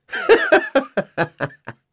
Crow-Vox_Happy3.wav